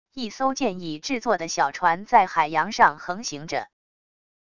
一艘建议制作的小船在海洋上横行着wav音频